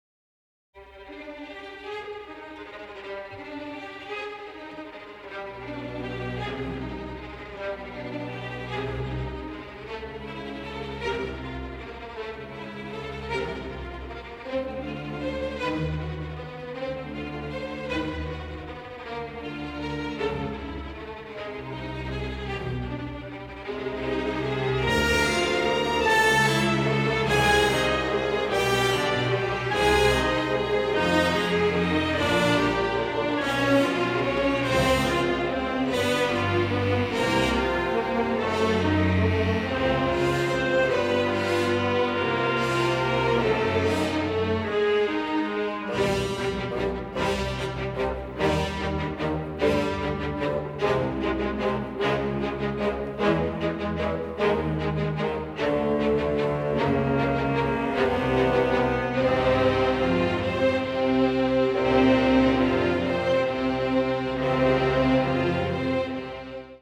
Original Calrec Soundfield - Stereo Microphone Mix